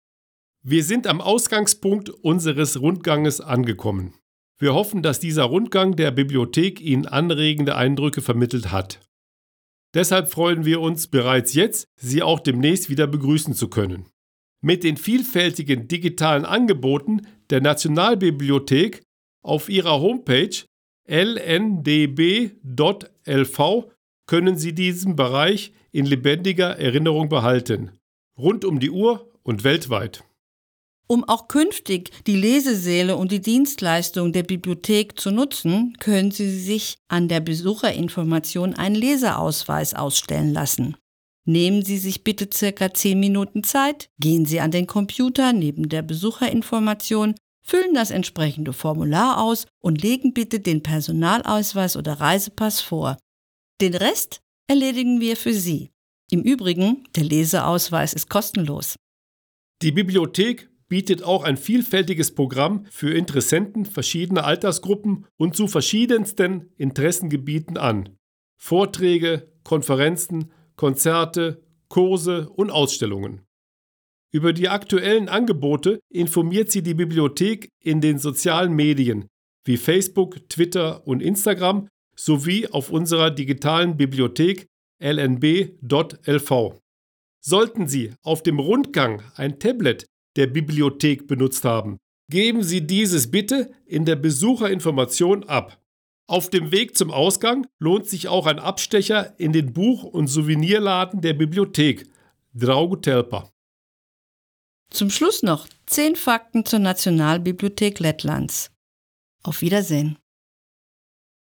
balss aktieris
Tūrisma gidi